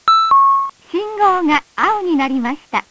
・押しボタンはなく歩行者用信号機から